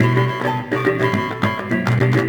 Percussion 23.wav